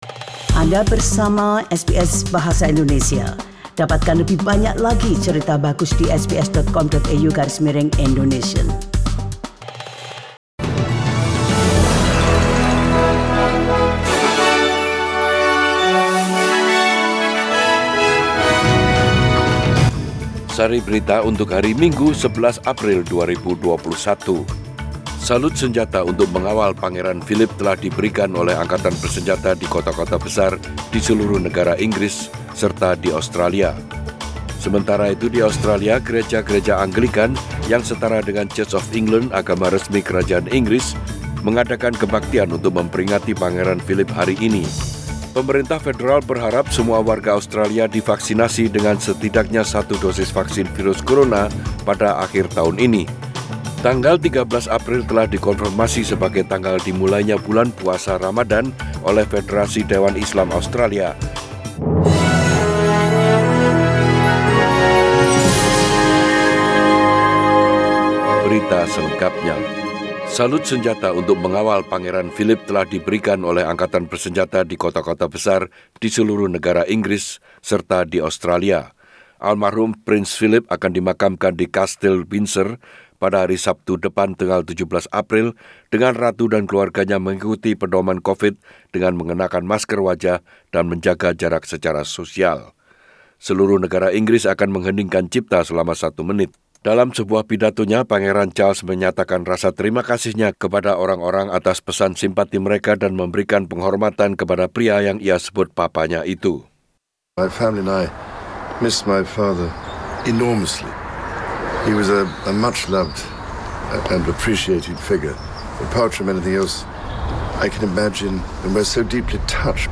SBS Radio News in Bahasa Indonesia - 11 April 2021
Warta Berita Radio SBS Program Bahasa Indonesia.